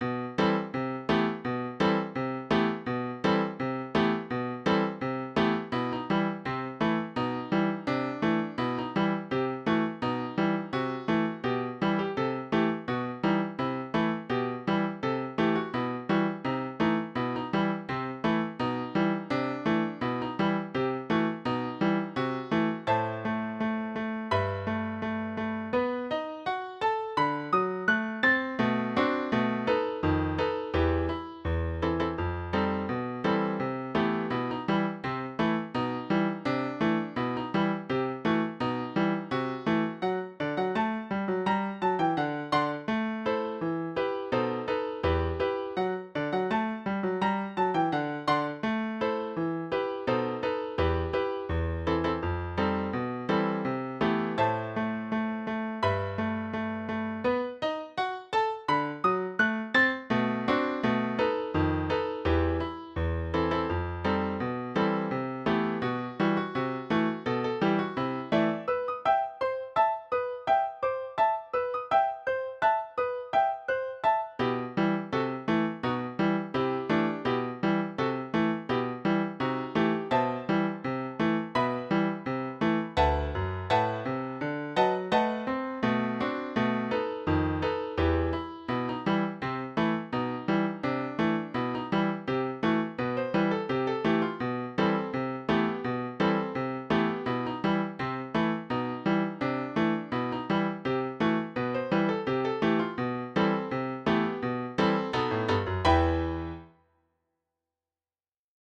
Scarica la base - mp3 3 Mb